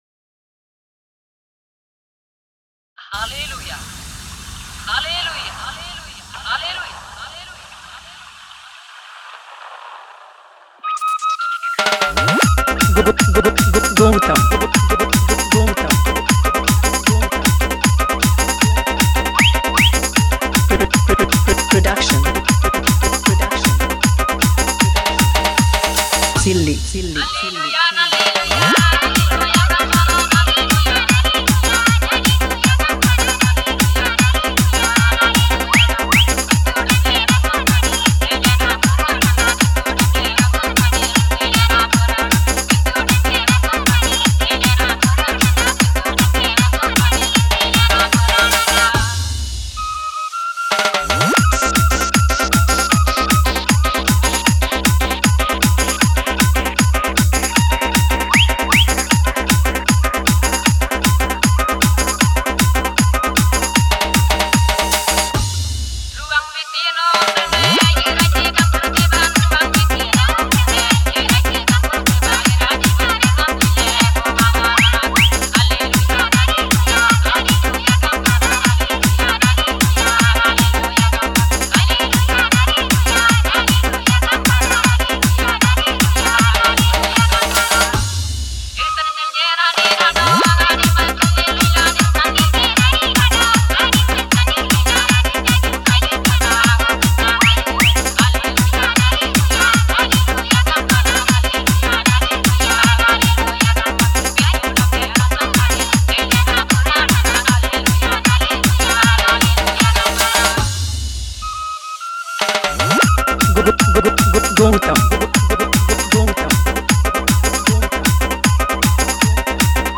vibrant Santali dance track